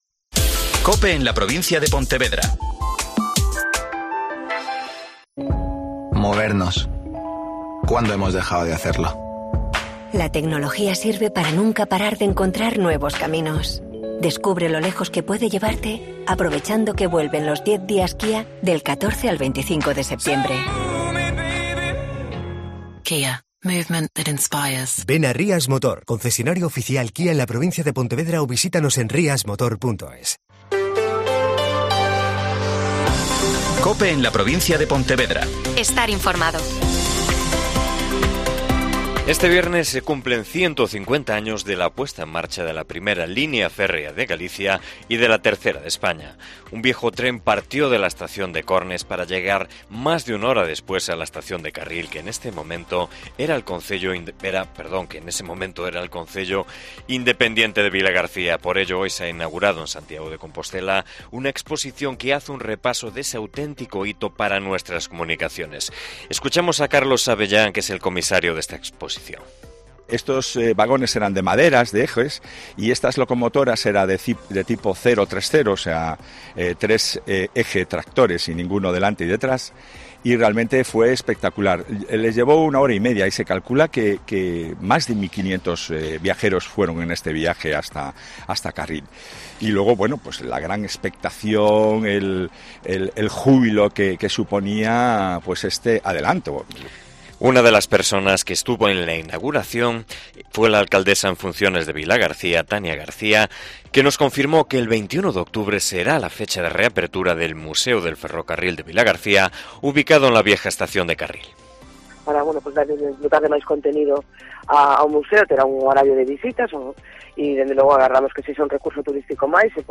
Mediodía COPE Pontevedra y COPE Ría de Arosa (Informativo 14:20h)